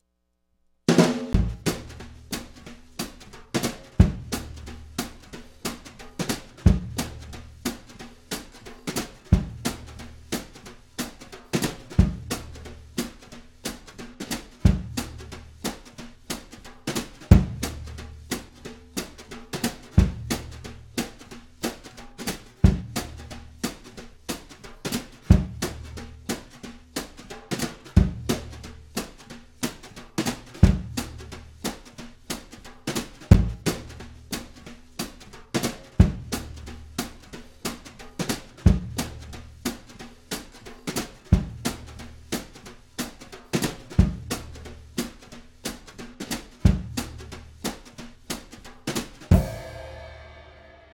Blues.wav